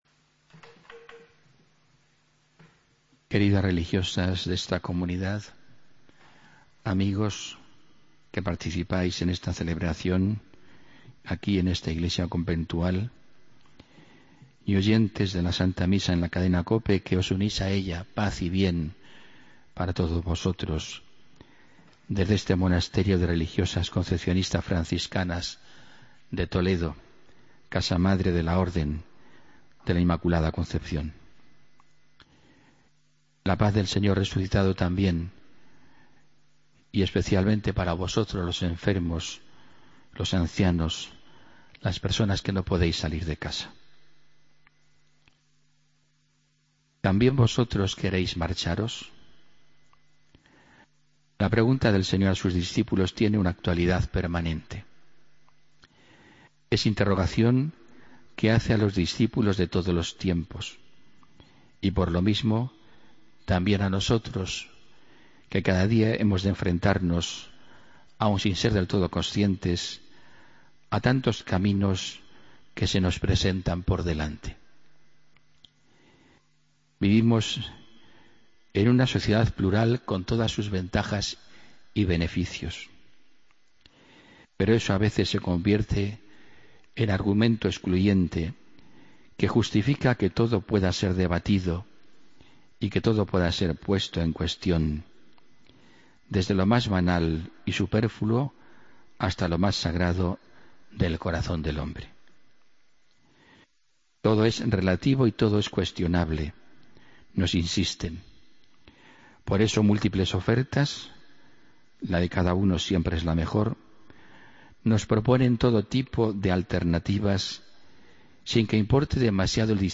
Homilía del domingo 23 de agosto de 2015